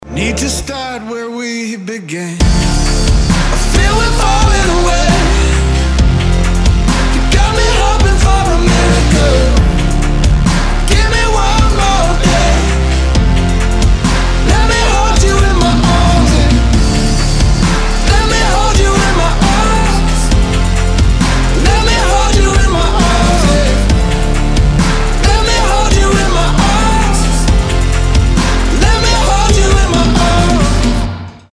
мужской вокал
красивый мужской голос
Alternative Rock